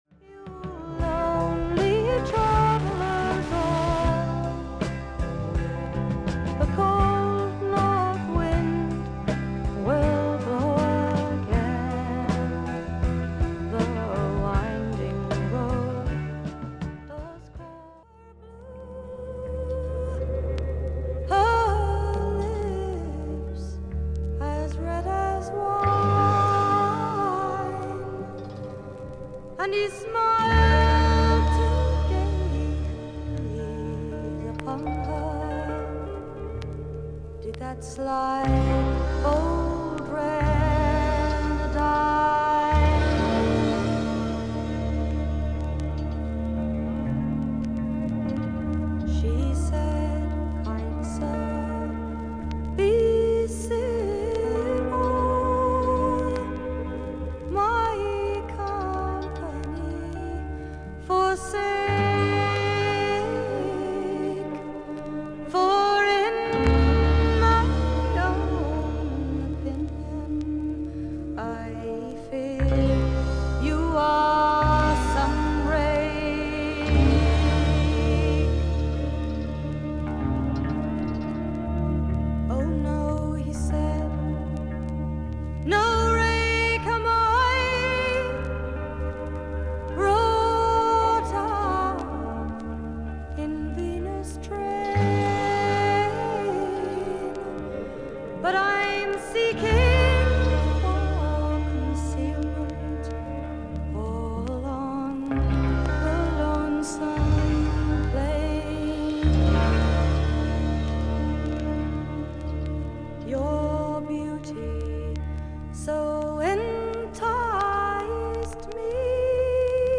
A2中盤から終盤に周回ノイズあり。
少々サーフィス・ノイズあり。クリアな音です。